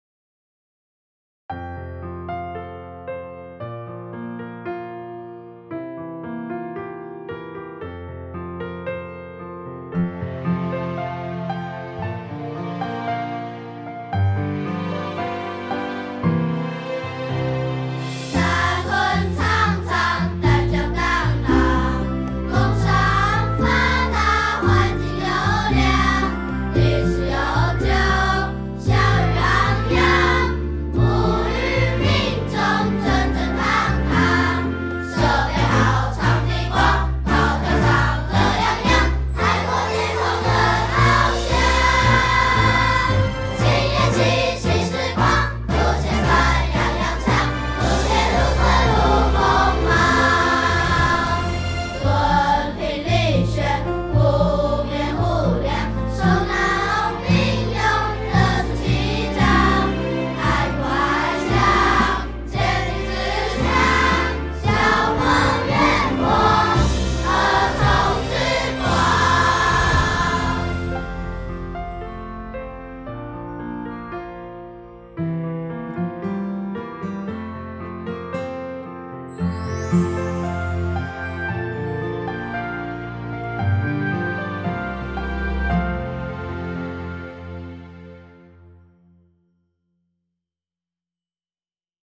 校歌---節奏樂隊版